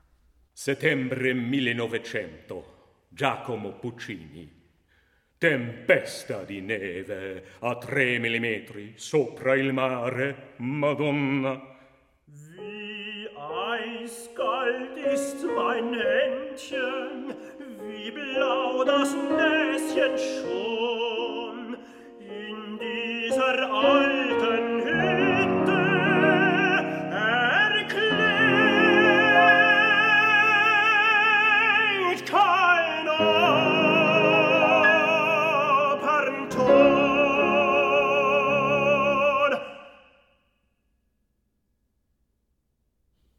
Albert Moeschinger: Giacomo Puccini, 1900 (Tenor, piano)